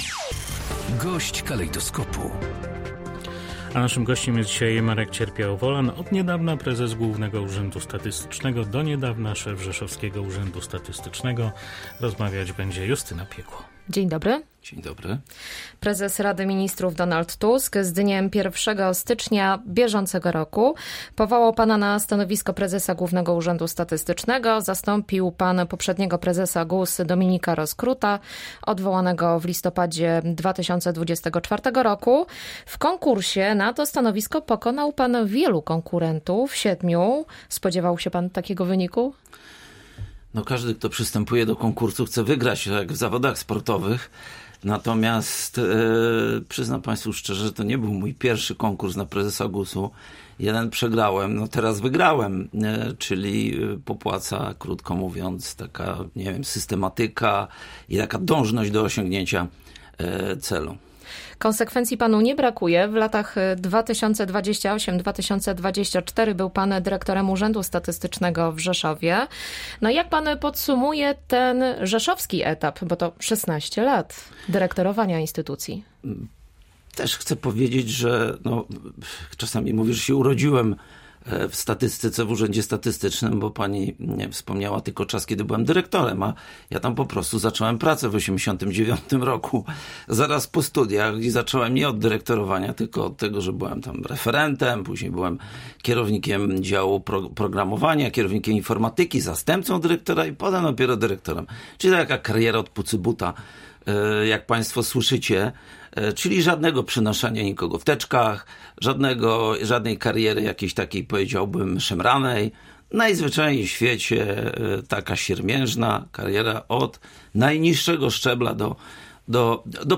GOŚĆ DNIA. Marek Cierpiał-Wolan, szef GUS